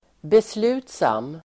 Uttal: [besl'u:tsam]